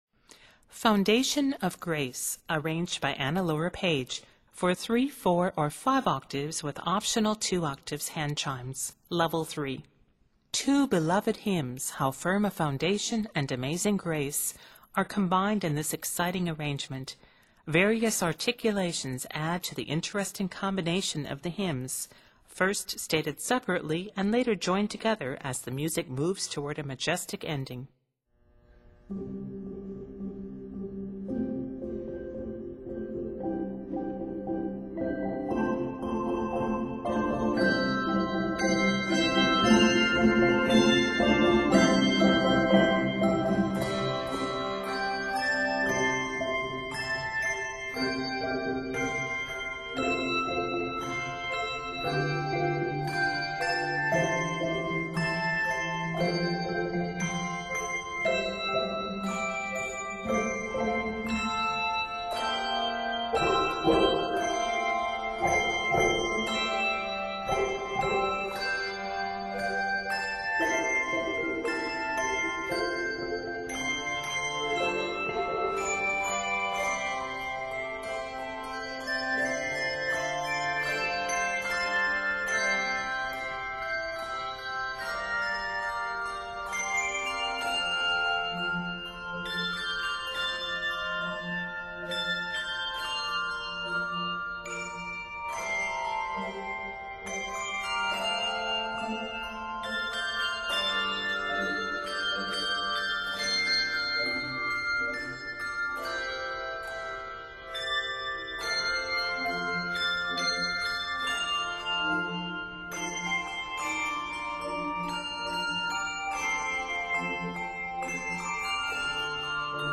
arranged in C Major and F Major